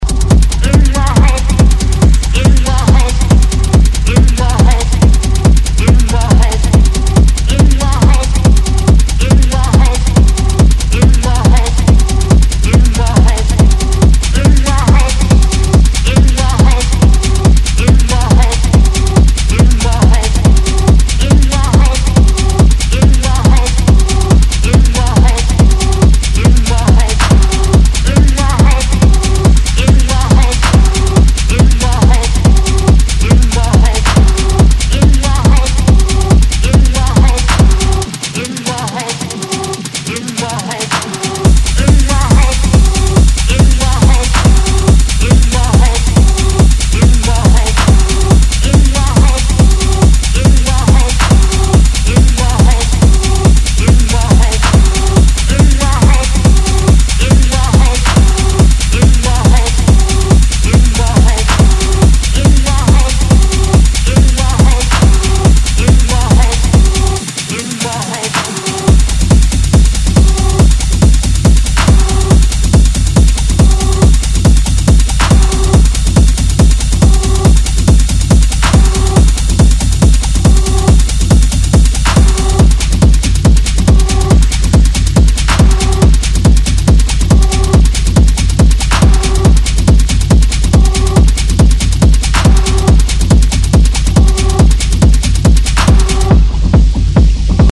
Electronic
Techno